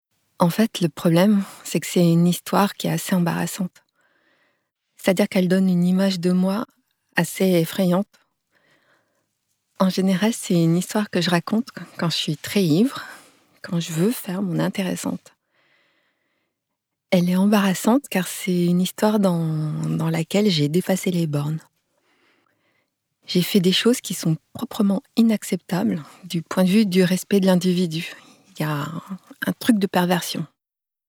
Chronique
Voix off
5 - 53 ans - Mezzo-soprano